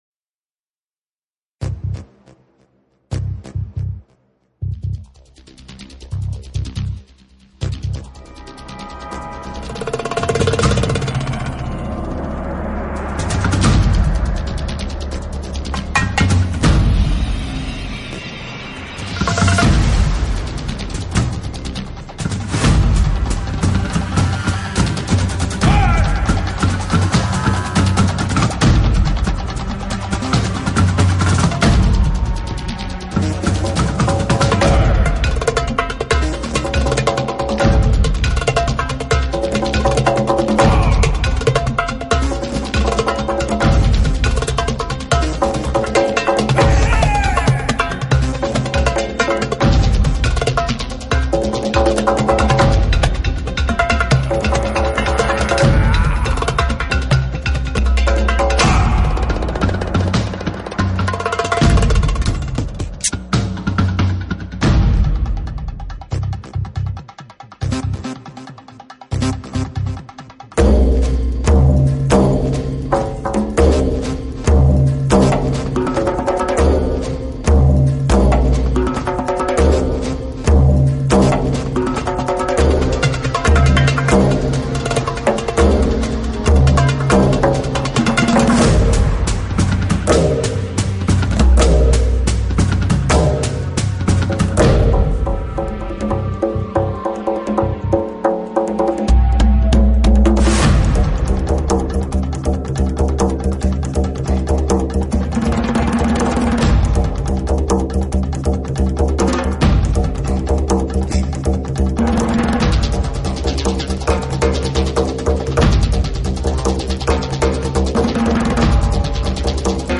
Low Quality